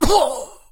Звуки боли
Подборка включает мужские и женские голоса с разной интенсивностью эмоций.